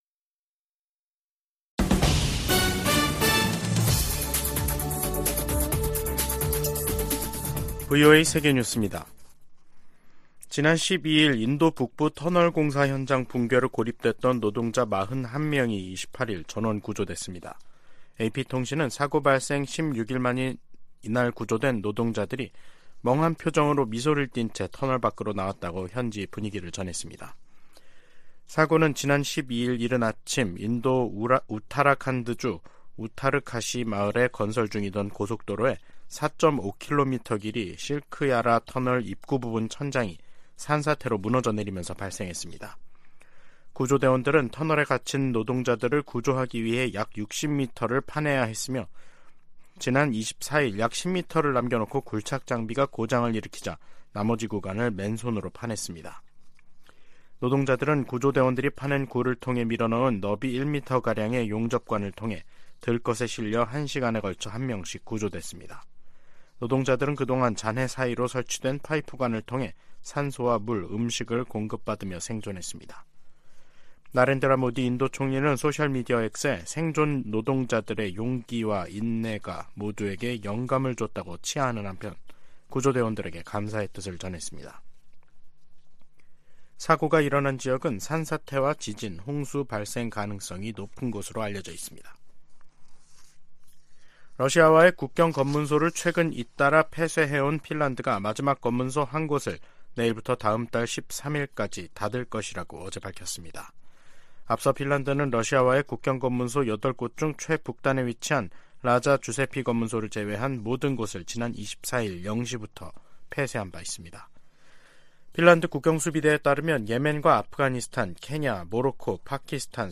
VOA 한국어 간판 뉴스 프로그램 '뉴스 투데이', 2023년 11월 29일 3부 방송입니다. 미국 정부는 북한의 정찰위성 발사를 규탄하면서 면밀히 평가하고, 러시아와의 협력 진전 상황도 주시하고 있다고 밝혔습니다. 미 국무부는 북한이 비무장지대(DMZ) 내 최전방 감시초소(GP)에 병력과 장비를 다시 투입한 데 대해 긴장을 부추기고 있다고 비판했습니다. 북한 정찰위성은 고화질 사진을 찍을 수 없다고 전문가들이 평가했습니다.